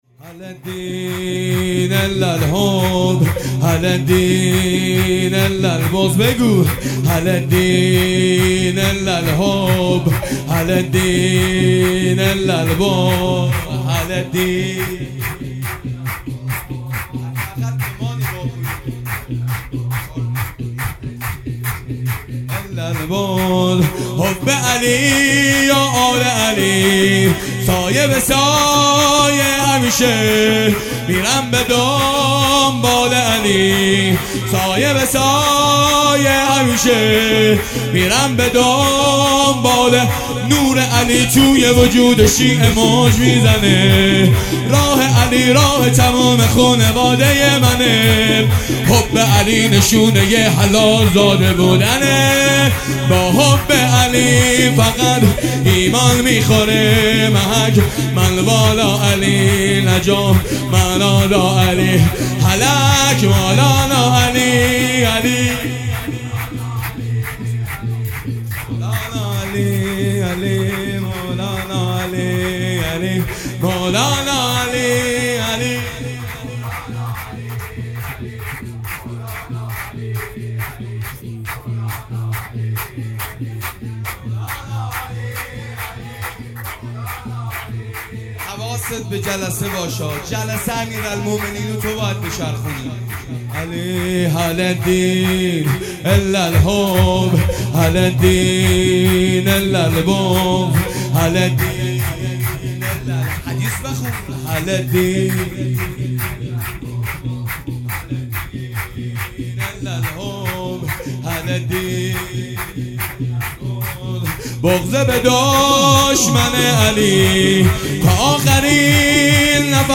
جشن ولادت امیرالمومنین (ع) 13 بهمن 1401